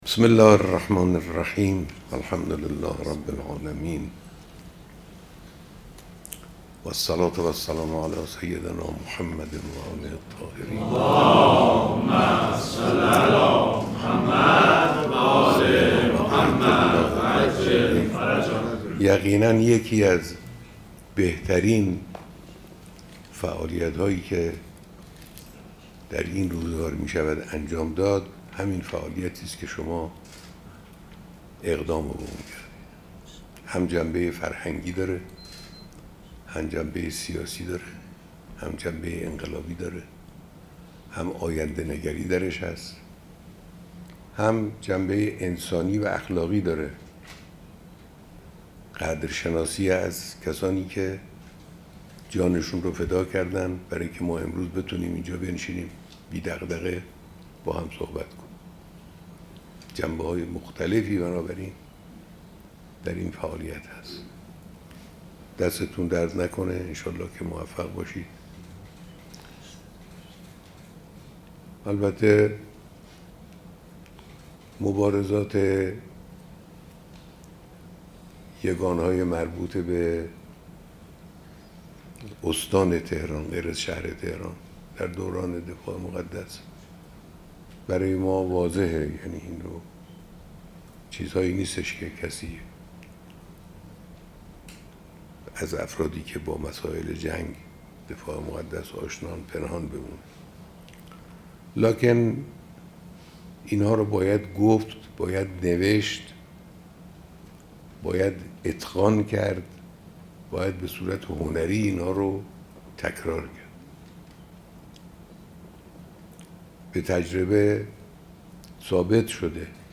بیانات دیدار دست‌اندرکاران کنگره بزرگداشت شهدای استان تهران